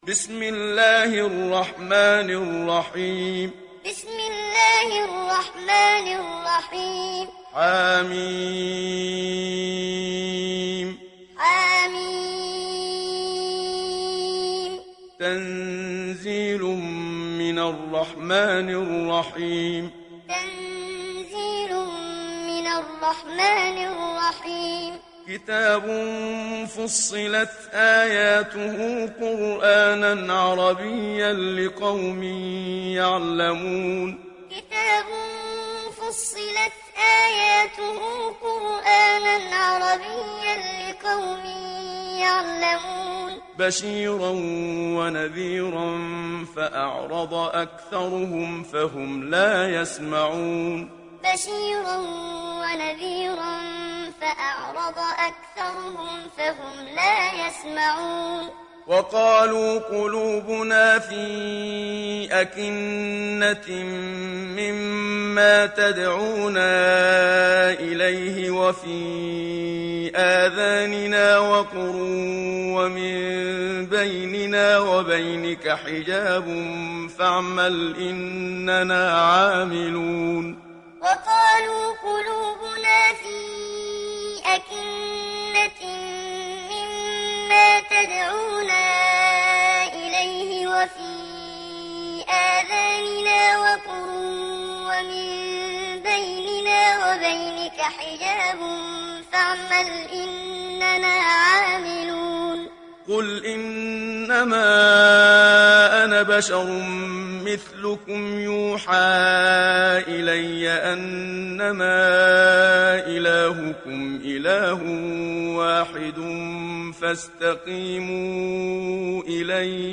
دانلود سوره فصلت محمد صديق المنشاوي معلم